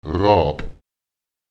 Lautsprecher ráb [raùb] der Vogel